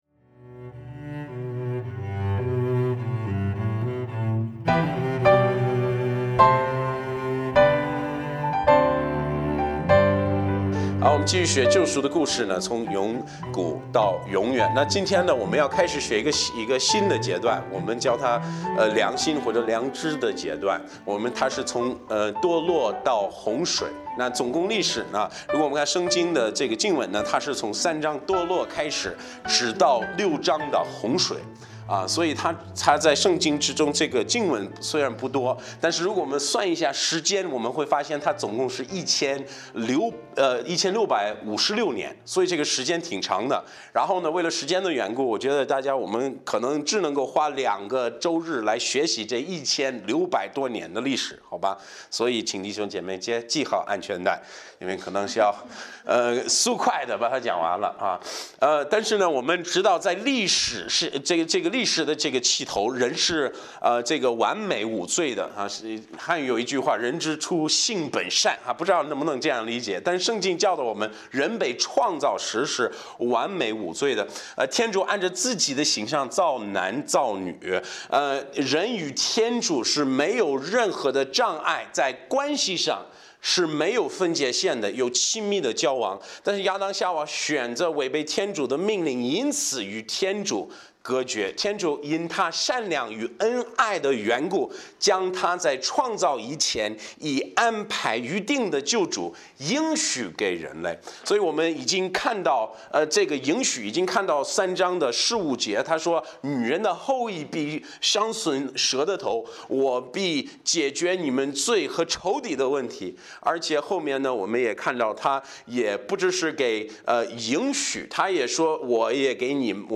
讲道者